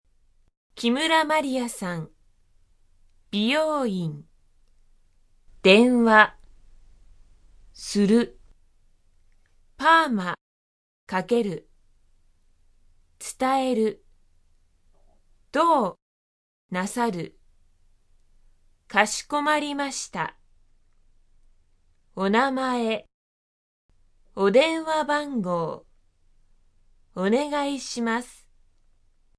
電話（自宅）
会話例●話す ●聞く ○読む ○書く 　希望・願望を述べる